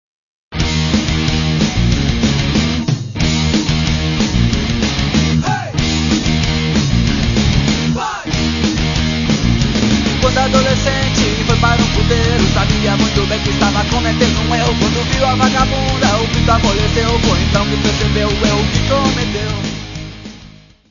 Music Category/Genre:  World and Traditional Music